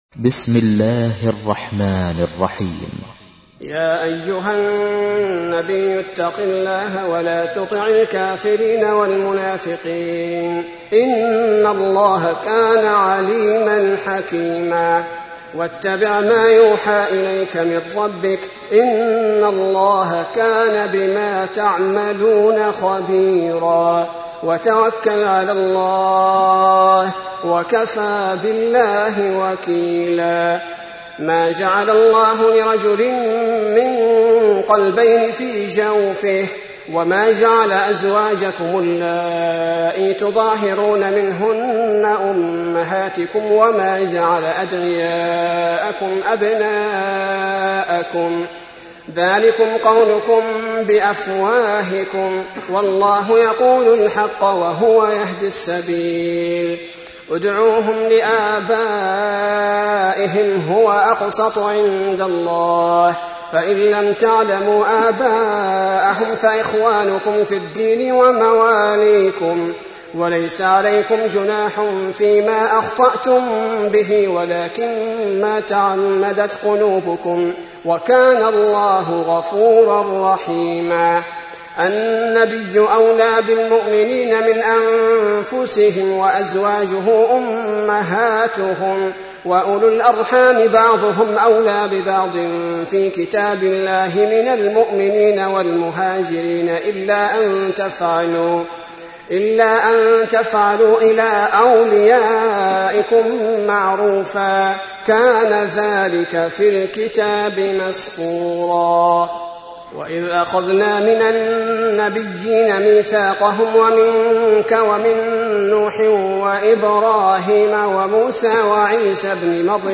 تحميل : 33. سورة الأحزاب / القارئ عبد البارئ الثبيتي / القرآن الكريم / موقع يا حسين